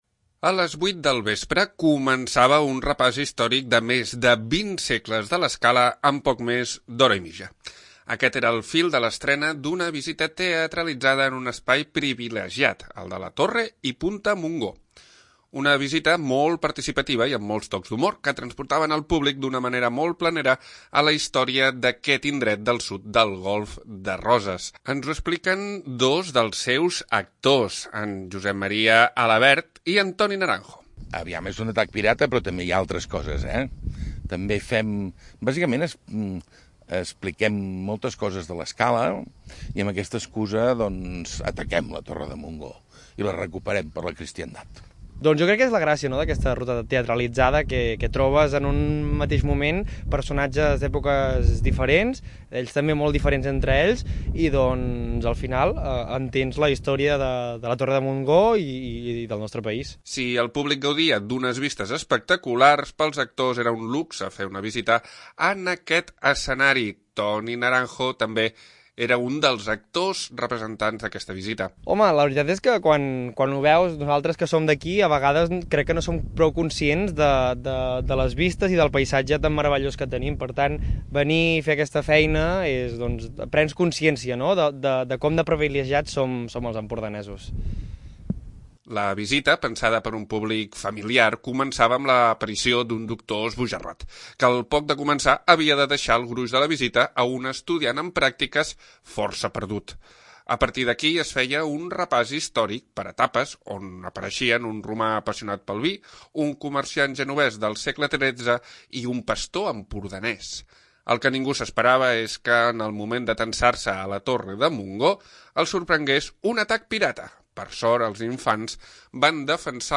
Turisme de l'Escala repassa la història de l'Escala, dels Indiketes a l'època moderna, en una visita teatralitzada a la Torre de Montgó. L'escenari únic de Punta Montgó acollia per primer cop aquest model de visita teatralitzada, on grans i petits van participar d'un repàs històric de l'Escala amb molts tocs d'humor i un atac pirata molt sonat.